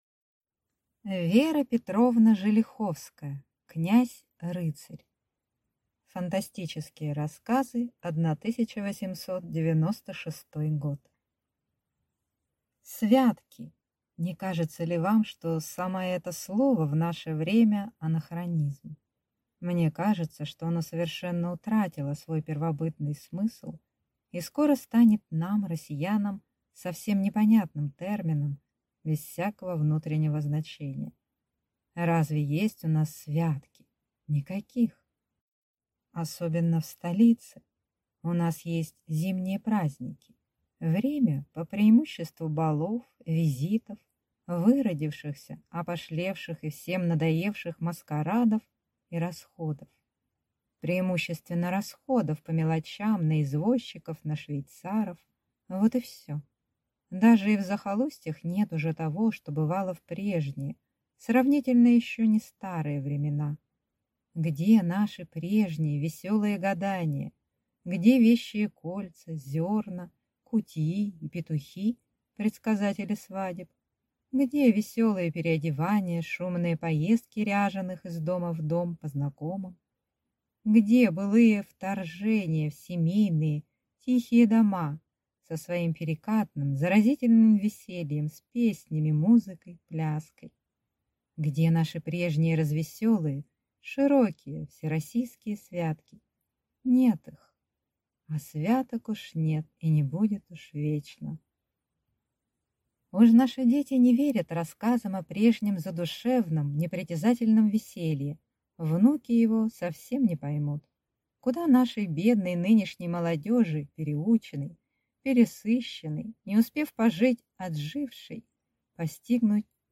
Аудиокнига Князь-рыцарь | Библиотека аудиокниг